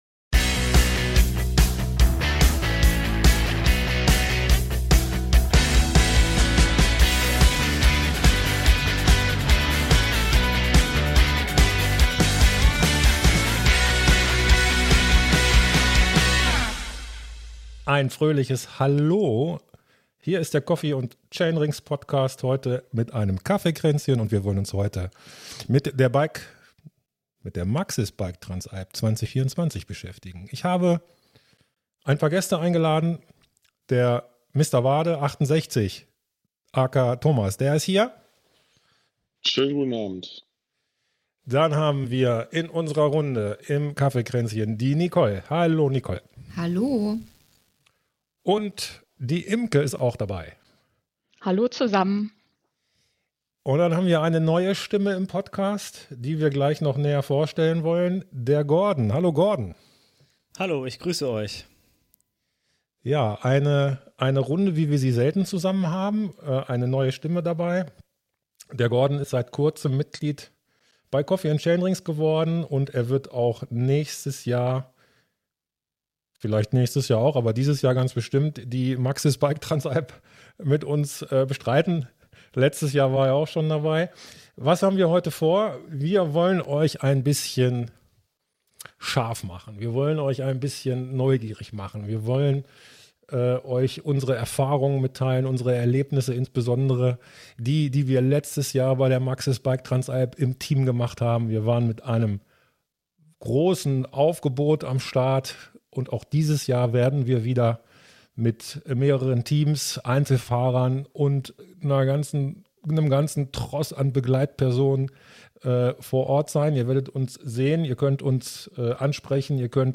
Die fünf sprechen im gewohnt lockeren Talk über die Motivation an der BTA teilzunehmen, befassen sich mit der Frage nach Fitnesslevel und dem geeigneten Trainingsansatz und sprechen natürlich auch über die Faszination dieses legendären Formates.